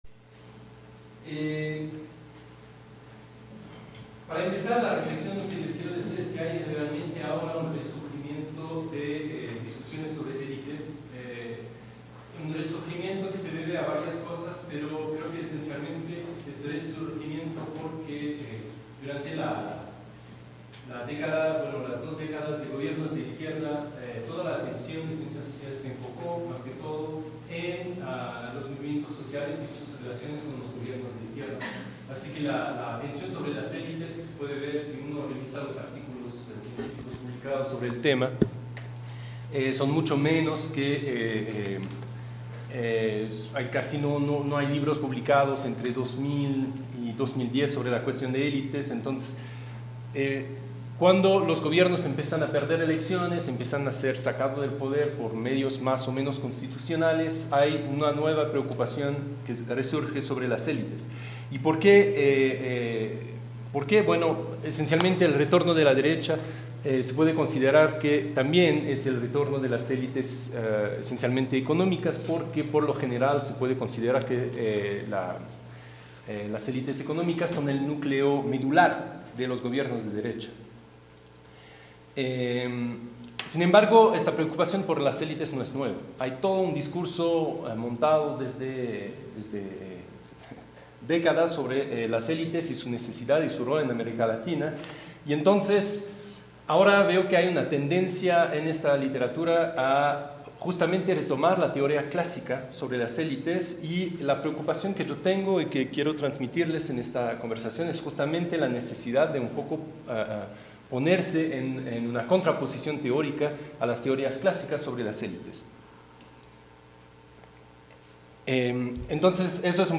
Conversatorio Periodizar las relaciones élites-Estado un método para su teorización